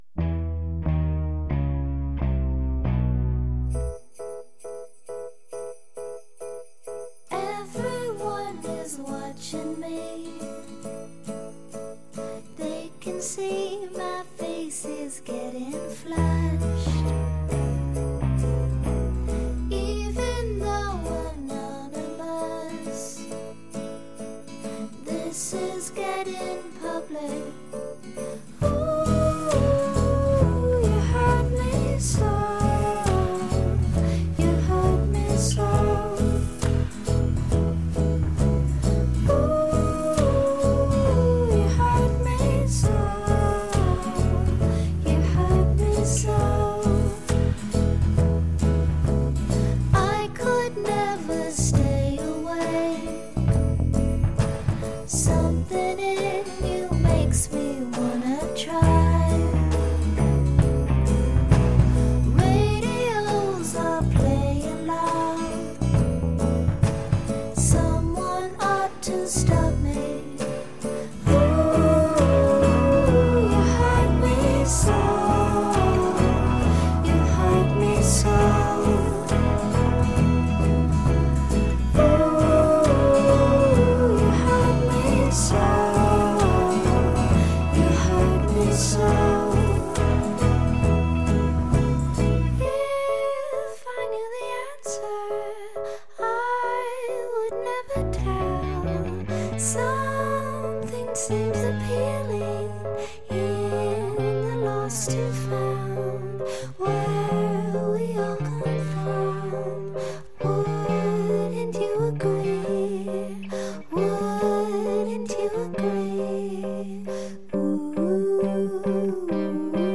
Senti i campanelli. Fanno un po’ Natale.